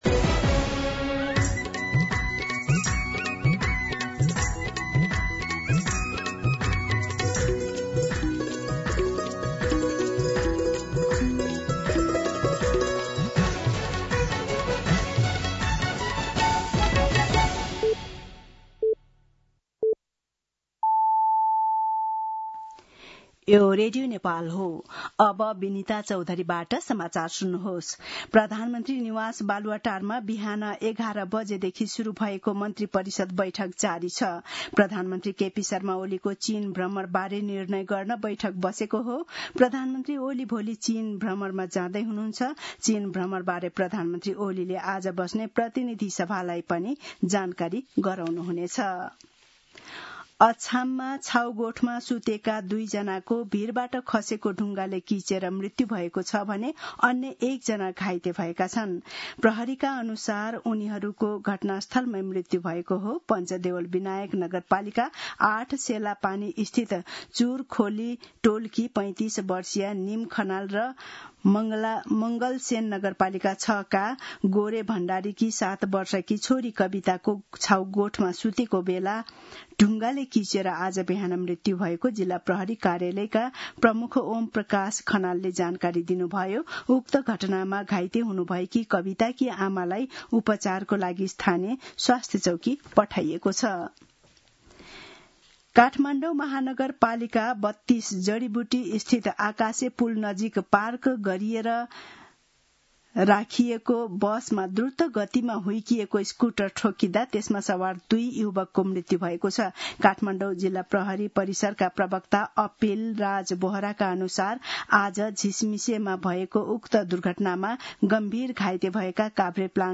दिउँसो १ बजेको नेपाली समाचार : १३ भदौ , २०८२
1pm-News-05-13.mp3